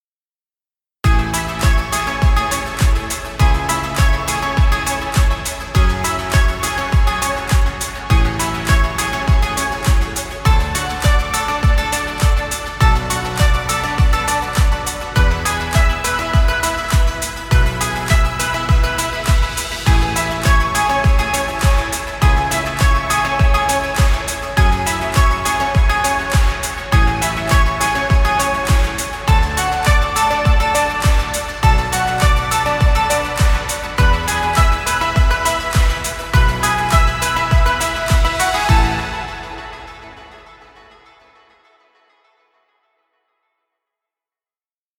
Motivational corporate music.